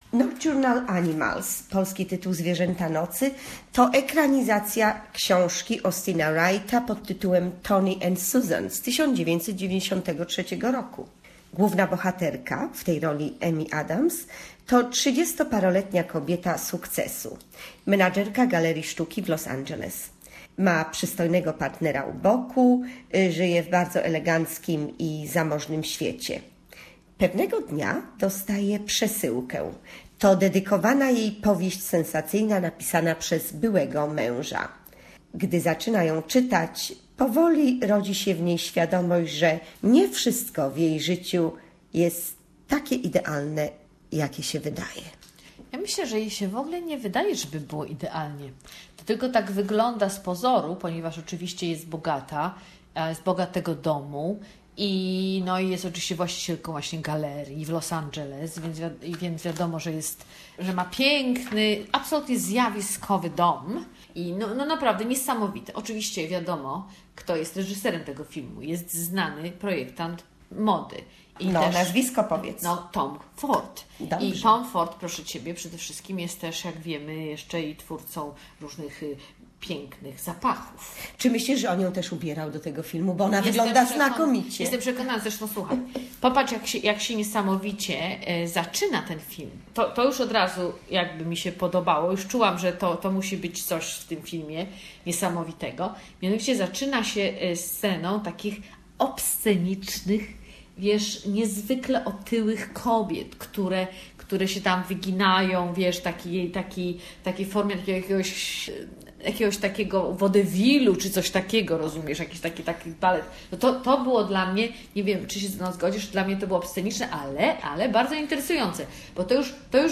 "Nocturnal Animals"- movie review